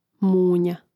múnja munja